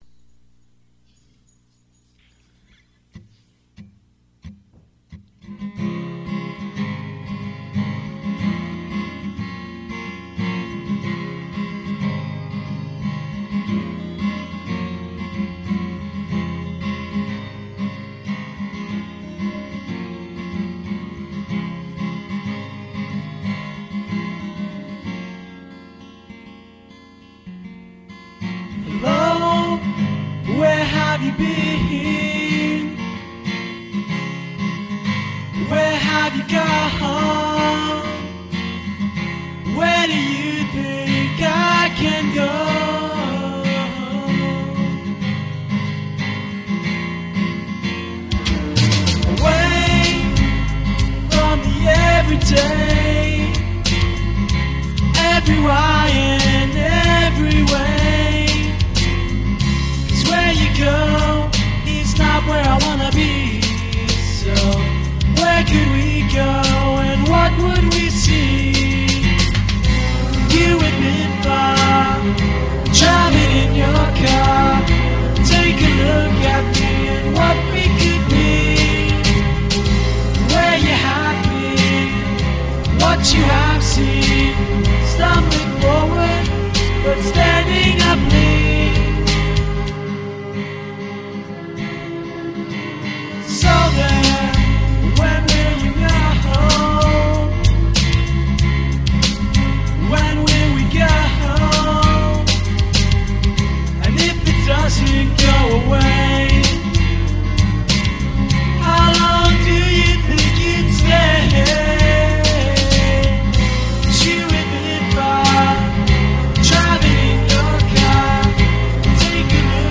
It's called Standing Up Lean, an acoustic pop thingy.
he did the vocals, the guitars and everything else, I played the drums
he also came up with the brilliant idea of violins.